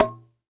Sfx Nerf Gun Shoot Sound Effect
sfx-nerf-gun-shoot.mp3